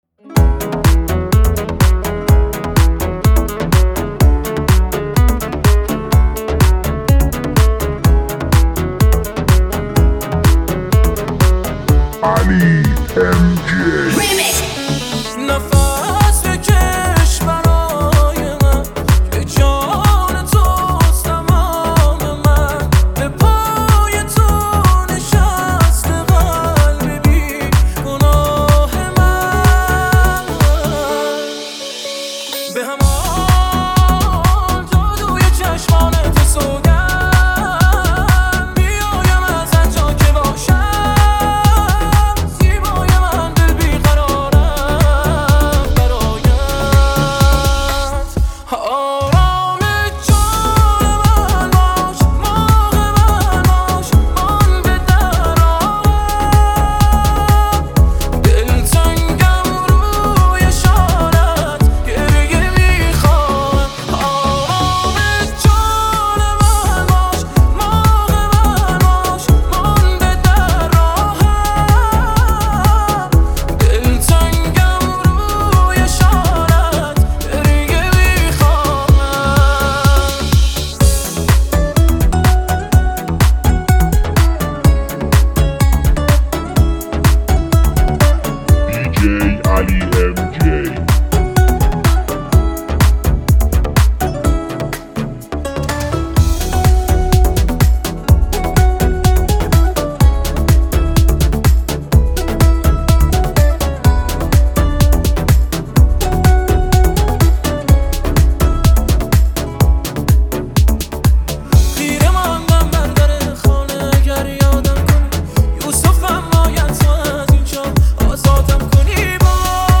دانلود ریمیکس تند بیس دار شاد